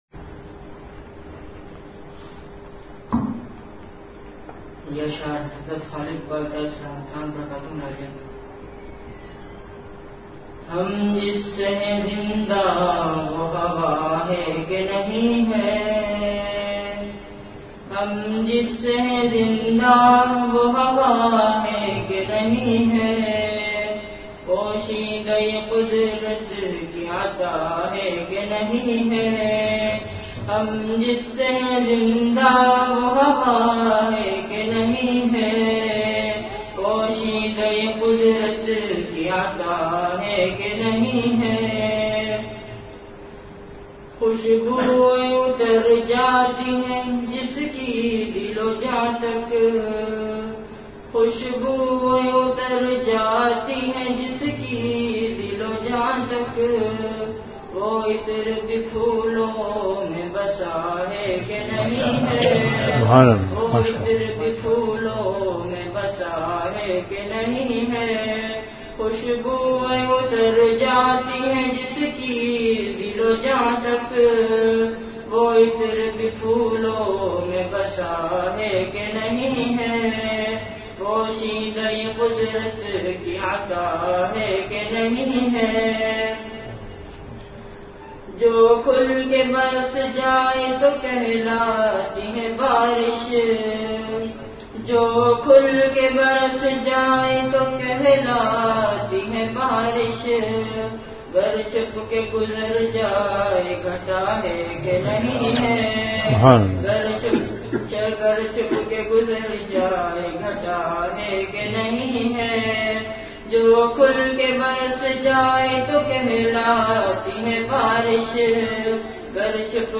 An Islamic audio bayan
Delivered at Home.
After Magrib Prayer